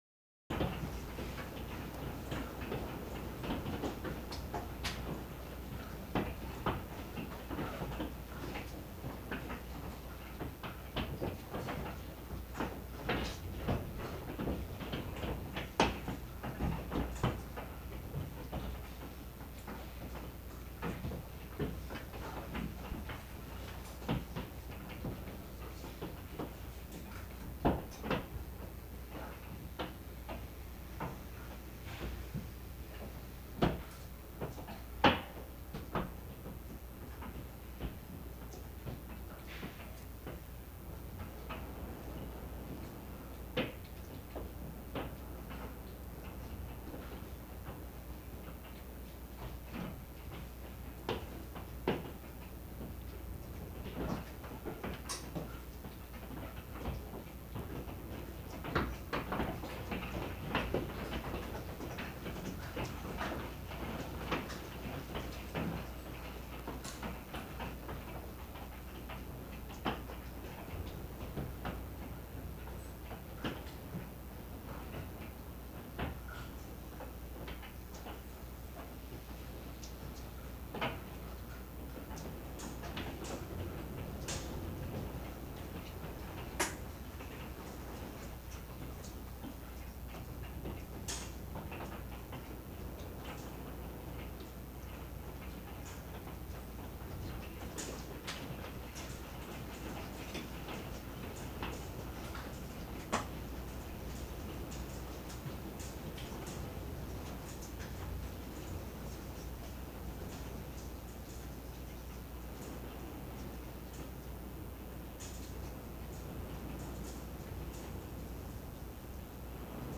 hail.ogg